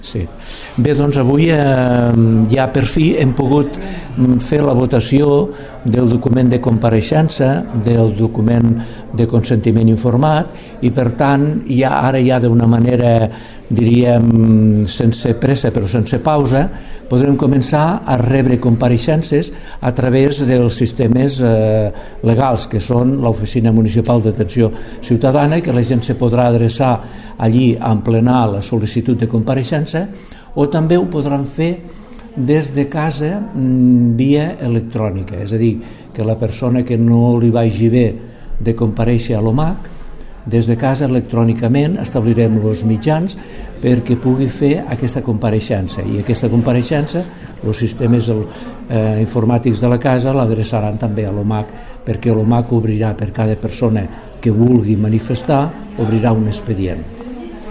tall-de-veu-del-president-de-la-comissio-especial-de-l1-doctubre